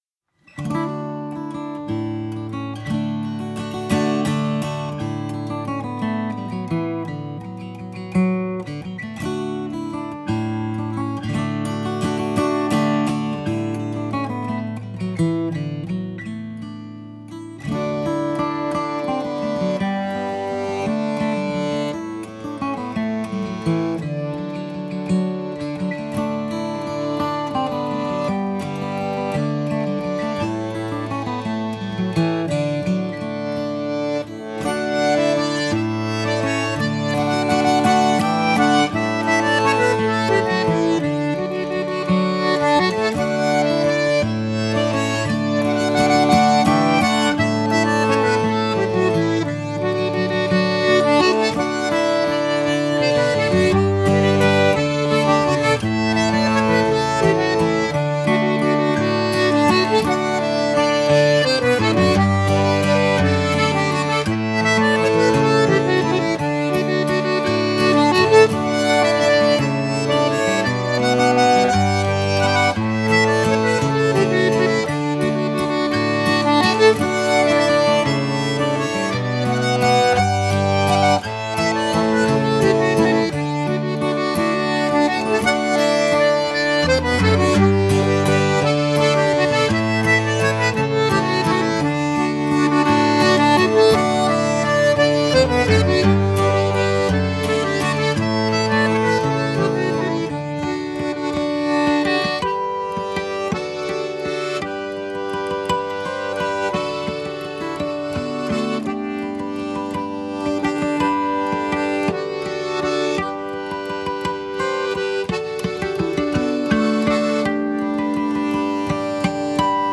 „Vaharulli valss” koosneb kahest osast ja helistikuks on D-duur. Noodis on kirjas kaks läbimängu, millest esimene koosneb ainult meloodiast ning teine meloodiast ja saatehäältest.
Poole pealt tuleb lõõts sisse, nii et ilmselt soundi skoori väga heaks ei saa (kui sul just lõõtsamees kõrval ei istu:), aga ajastust mõõdab sellegipoolest täpselt: